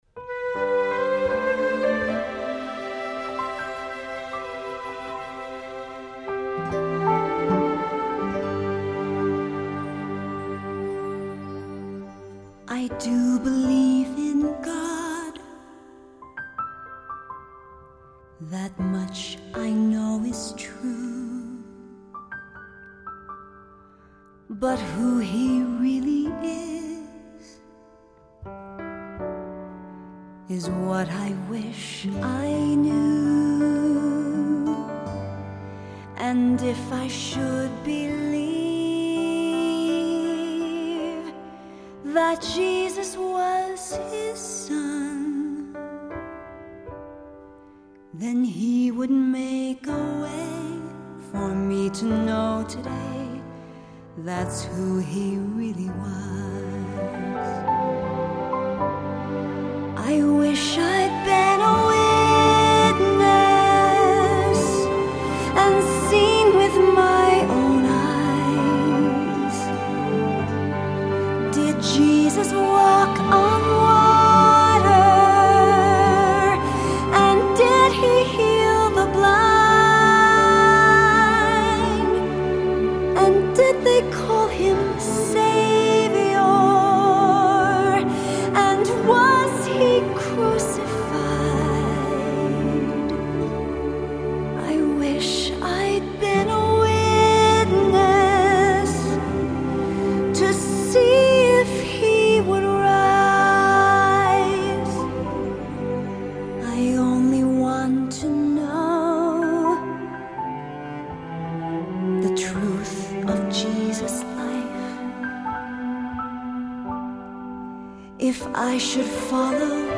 a new musical presentation for readers, soloists, and chorus